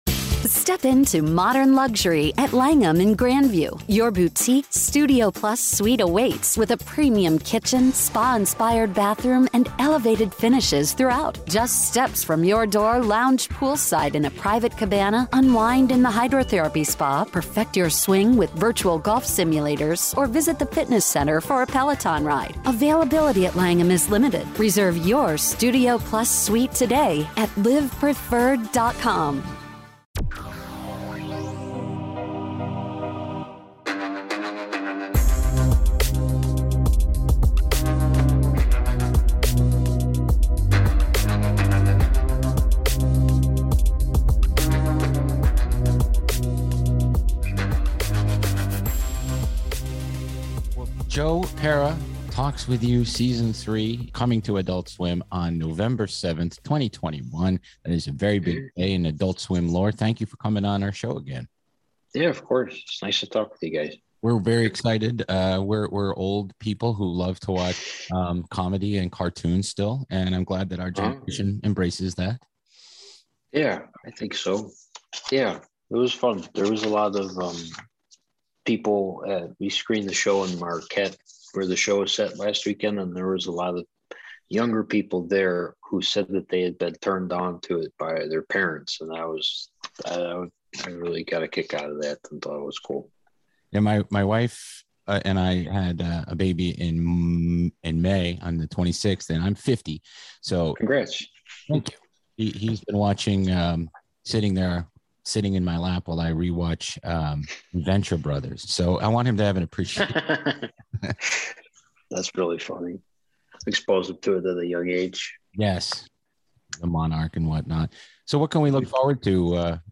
Radio Labyrinth Presents - Interviews - Joe Pera Talks With You